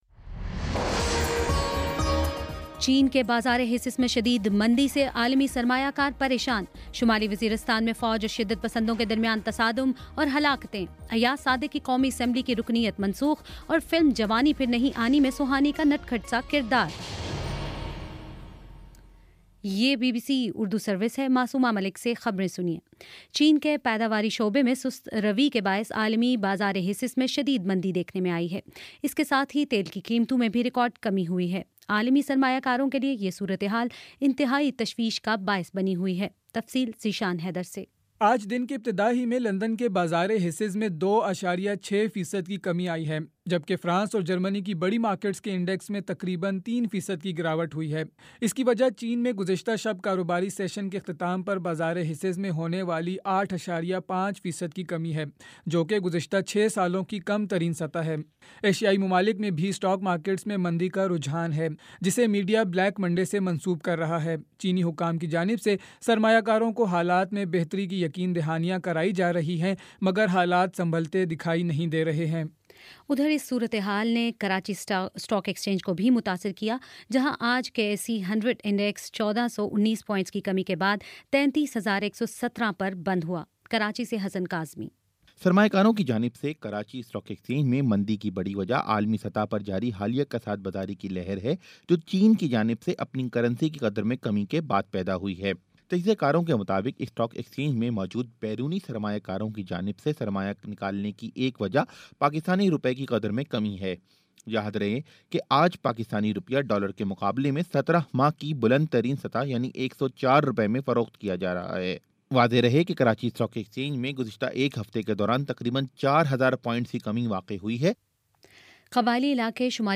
اگست 24: شام چھ بجے کا نیوز بُلیٹن